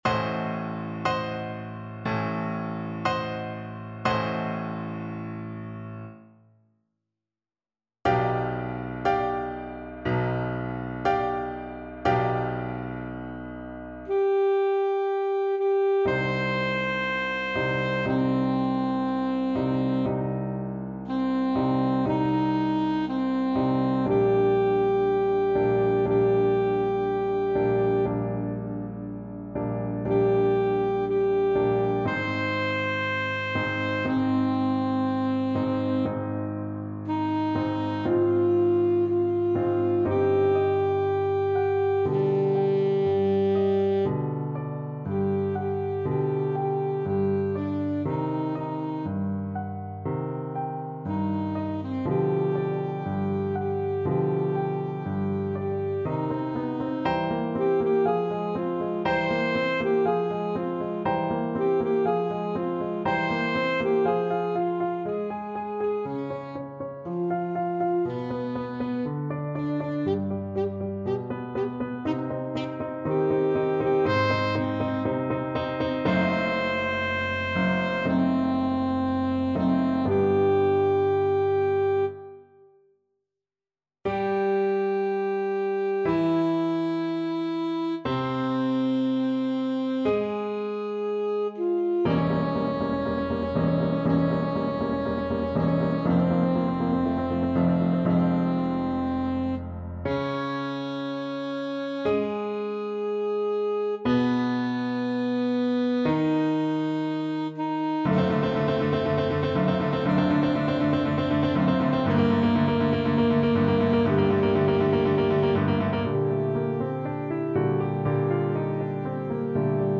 Alto Saxophone
C minor (Sounding Pitch) A minor (Alto Saxophone in Eb) (View more C minor Music for Saxophone )
Andante
4/4 (View more 4/4 Music)
Classical (View more Classical Saxophone Music)
Dramatic & Epic music for Alto Saxophone